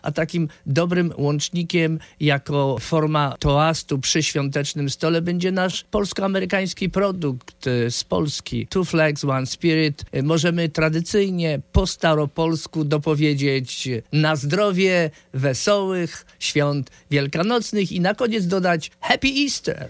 W studiu Radia Deon Chicago